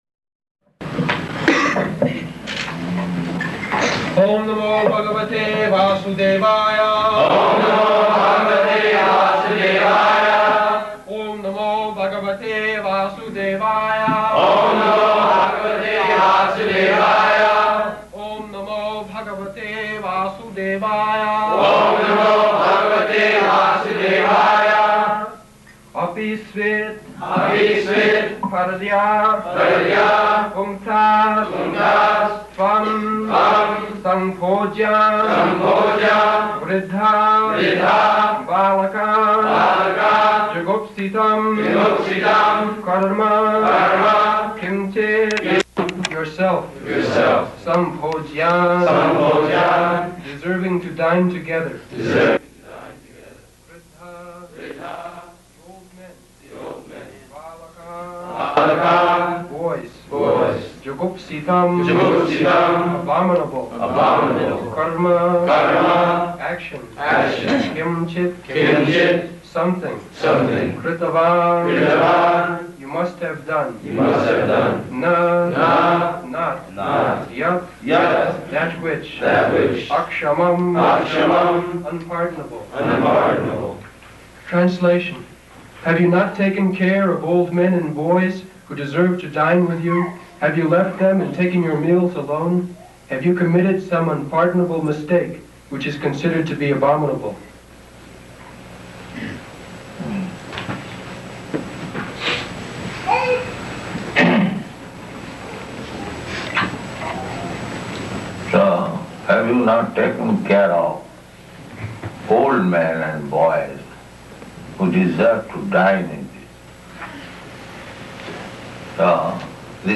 -- Type: Srimad-Bhagavatam Dated: April 7th 1973 Location: New York Audio file
[Prabhupāda and devotees repeat] [leads chanting of verse, etc.]